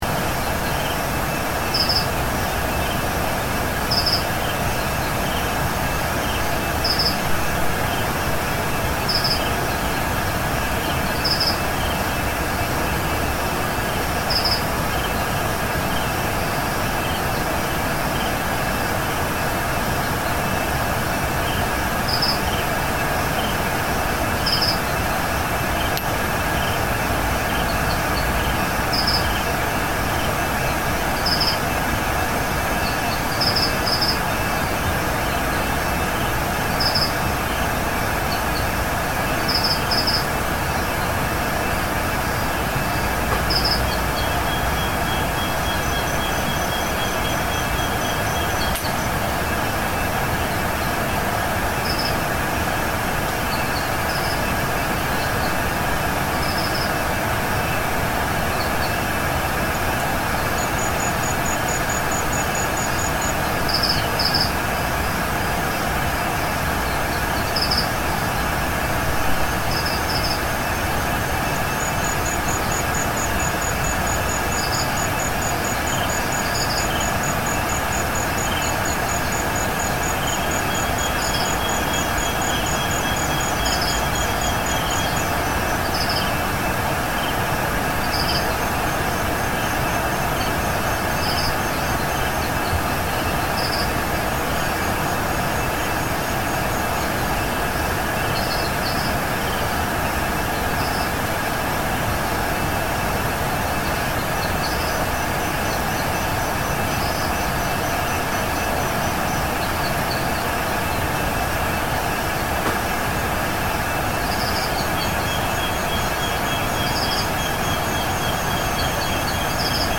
It is around 11.00 pm at Malaika camp, Kenya. The sound of the river is always present. Different types of birds are singing. Hippos are aroung the area and can be heard.